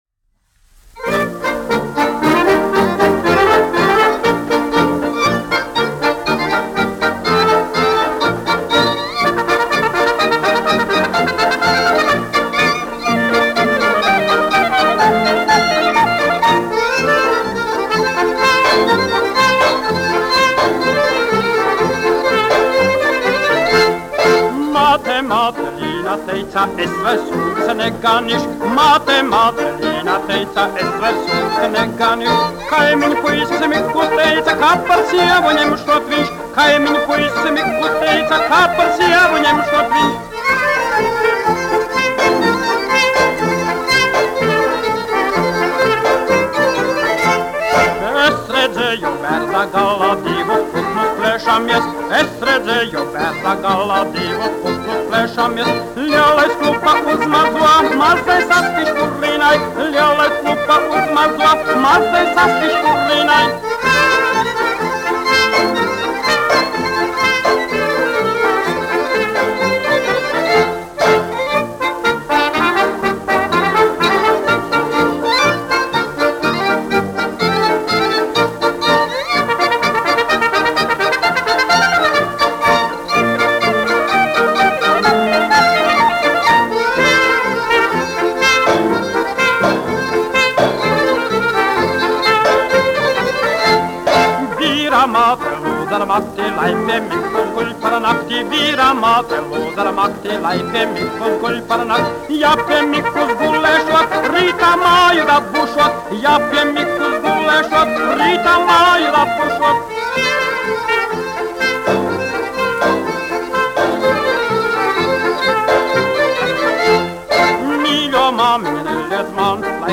1 skpl. : analogs, 78 apgr/min, mono ; 25 cm
Polkas
Skaņuplate
Latvijas vēsturiskie šellaka skaņuplašu ieraksti (Kolekcija)